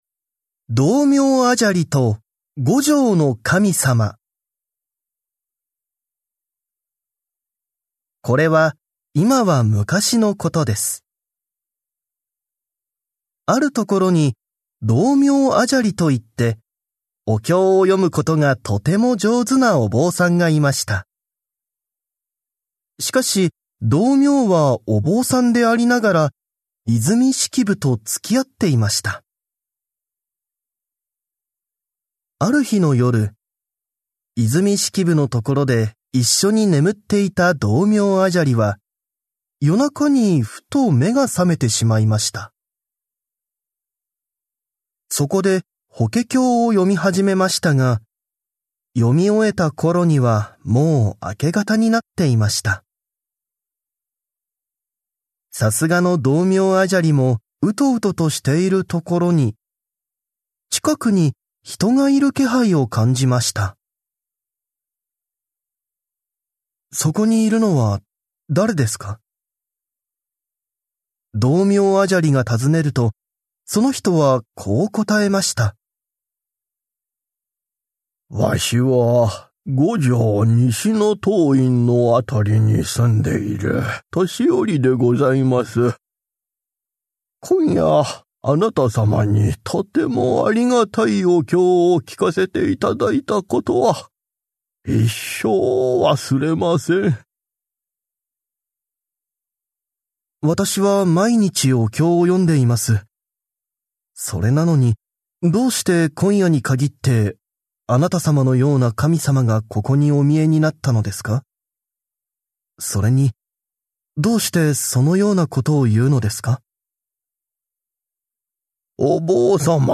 [オーディオブック] 宇治拾遺物語（聴いて親しむ日本の古典）
ふしぎな話、こわい話、珍しい話など、 面白くて奥の深い短いお話、全197作品をお子さま向けにわかりやすく、情感豊かに収録しています。